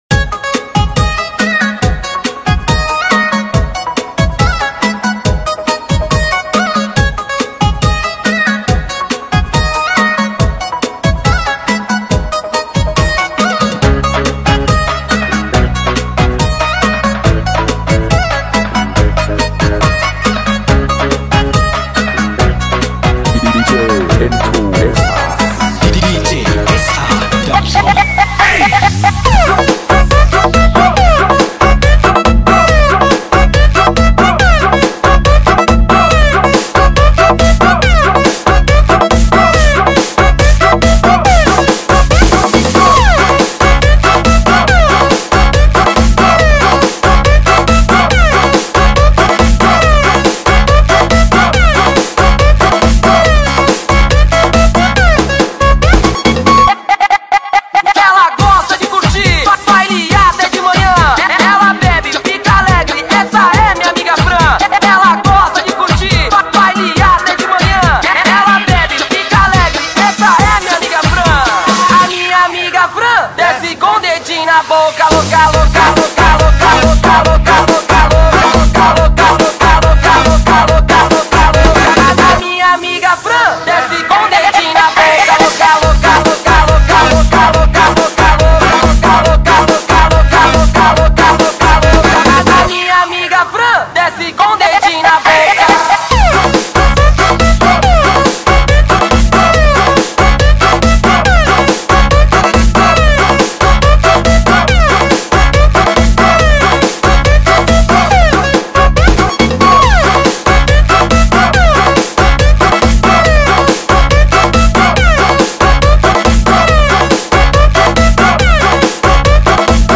house dan remix